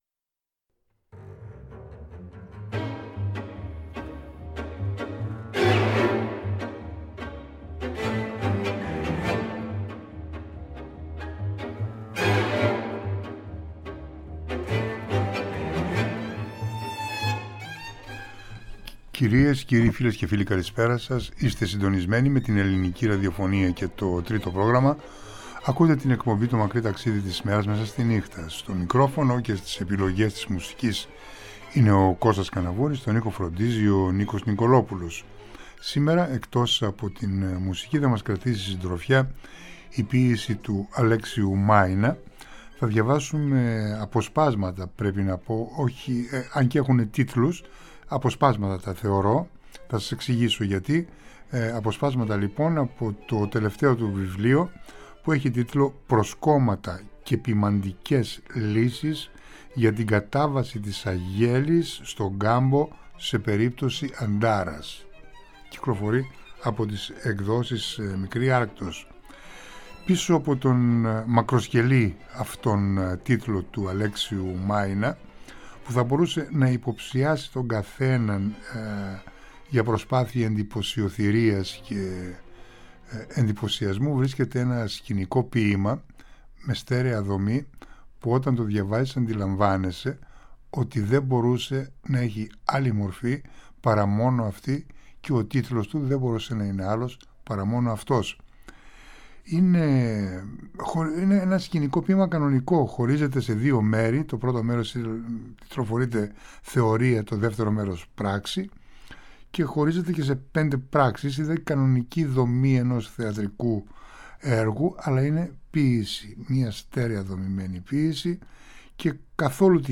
σε απευθείας μετάδοση.